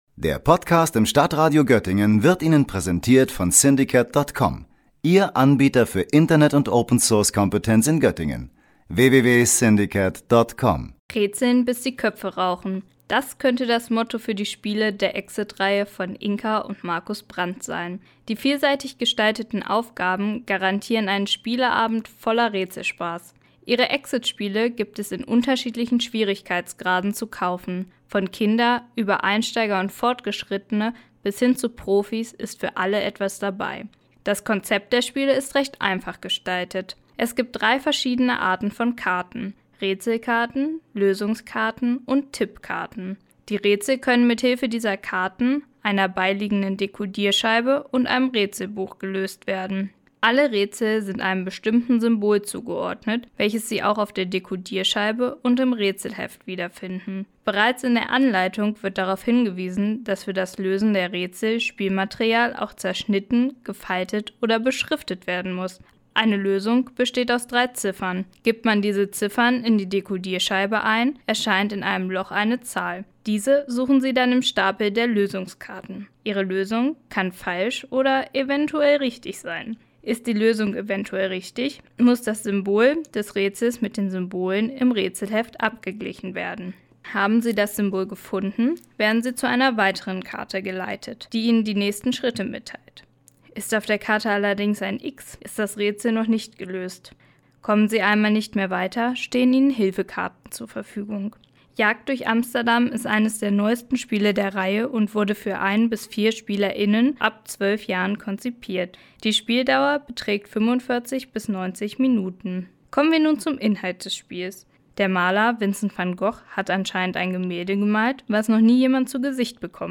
Beiträge > Rezension: Exit - Jagd durch Amsterdam - StadtRadio Göttingen